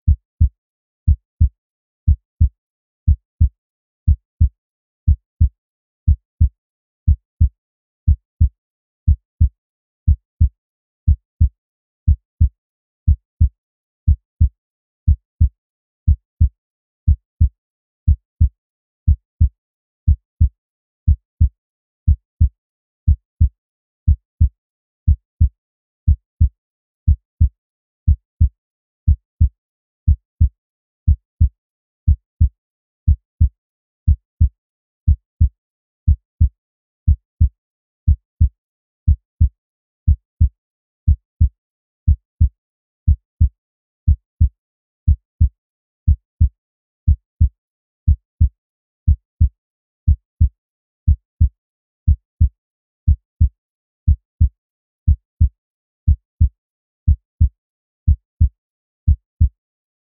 دانلود صدای قلب مادر برای جنین از ساعد نیوز با لینک مستقیم و کیفیت بالا
جلوه های صوتی
برچسب: دانلود آهنگ های افکت صوتی انسان و موجودات زنده دانلود آلبوم صدای ضربان قلب انسان از افکت صوتی انسان و موجودات زنده